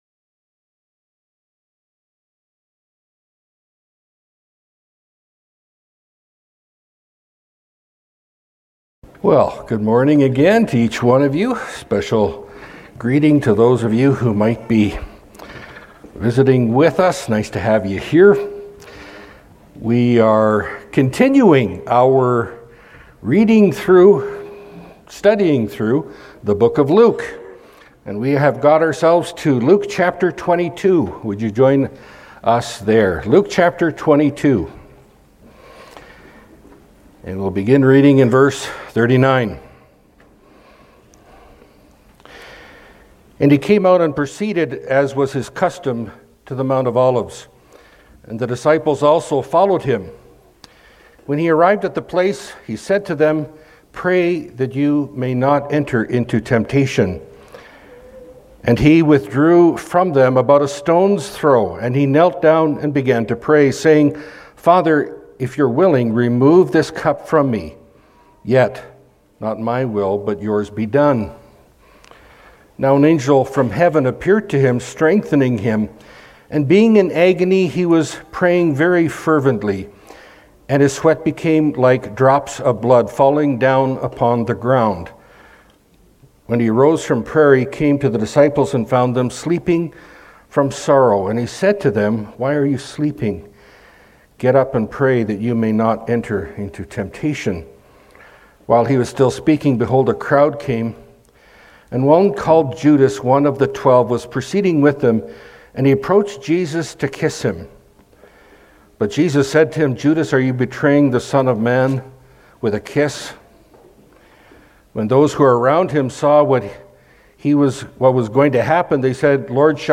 Pulpit Sermons Key Passage